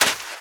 High Quality Footsteps
STEPS Sand, Run 16.wav